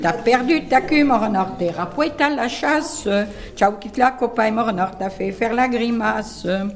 Divertissements d'adultes - Couplets à danser
danse : branle : courante, maraîchine
Pièce musicale inédite